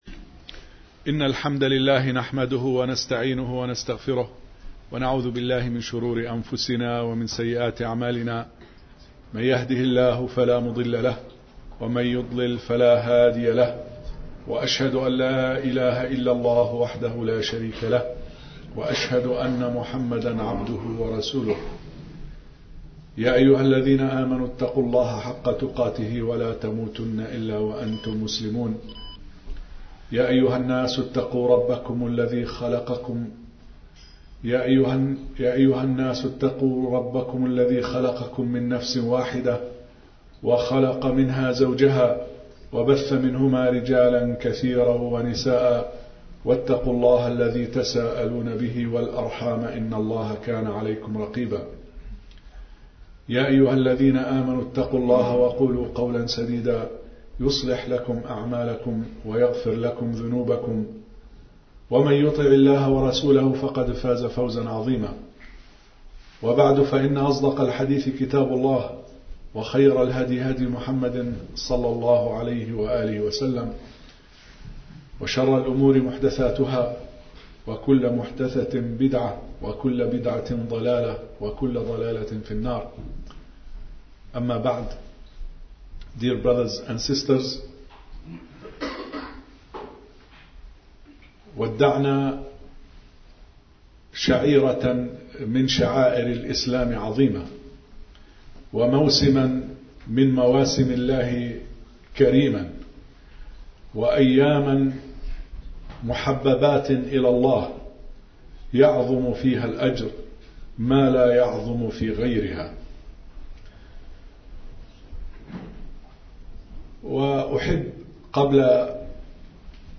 Audio Khutbah